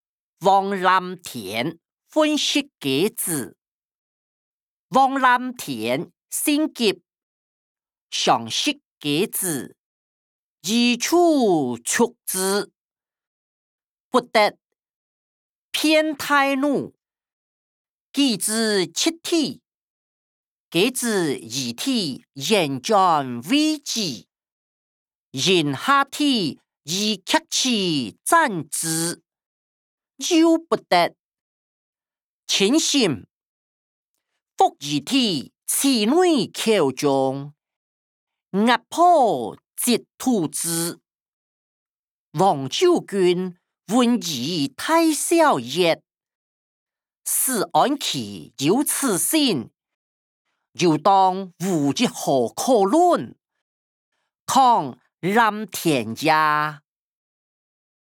小說-王藍田忿食雞子音檔(大埔腔)